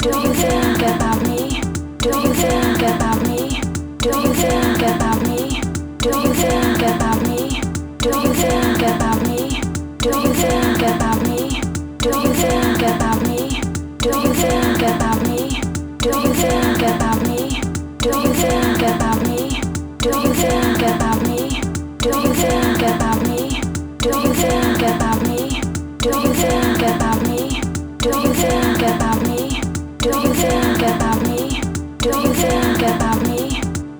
working on mixing and multiple tracks in DAW gets tricky still learning and experimenting!